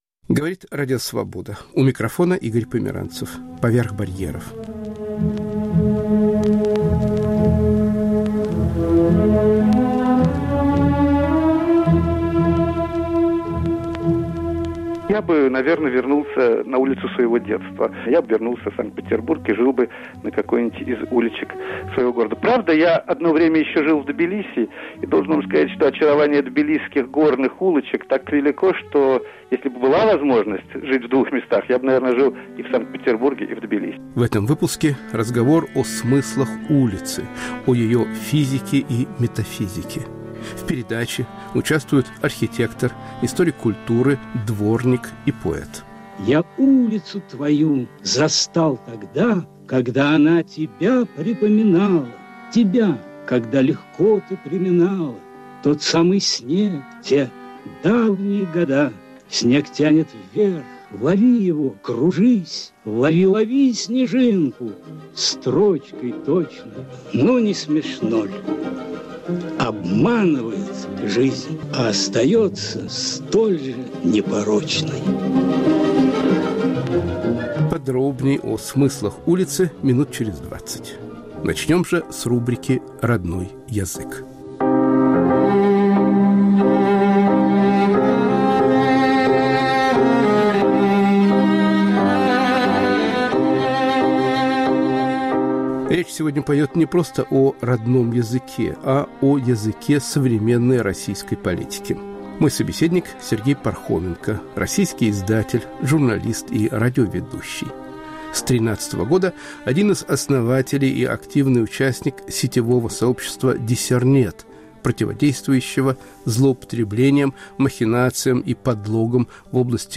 Политика и язык. Беседа с журналистом Сергеем Пархоменко.
Говорят архитектор, историк культуры, дворник и поэт.